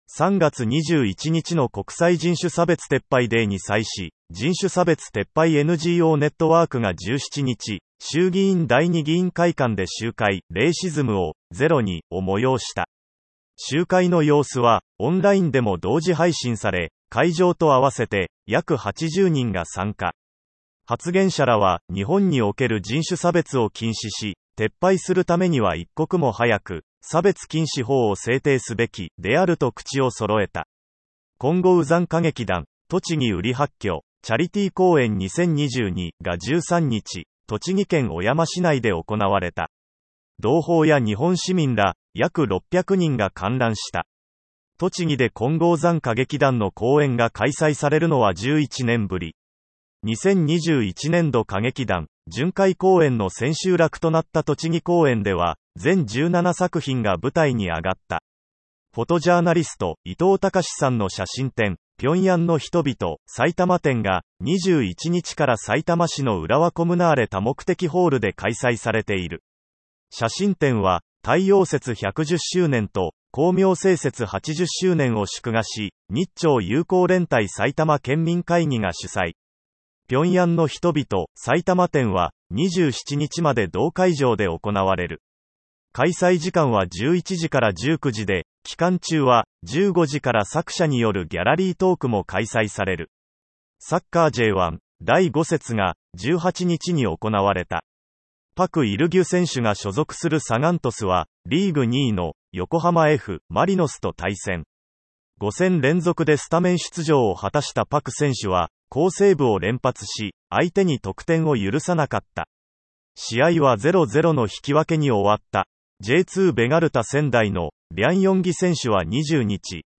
「聴くシンボ」は、朝鮮新報電子版 DIGITAL SINBOのニュースを音声でお聞きいただけます。同サービスでは、1週間の主要ニュースをピックアップし、毎週日曜日にダイジェストでお届けします。
※音声読み上げソフトを導入しているため、音声ニュースの中で発音が不自然になることがあります。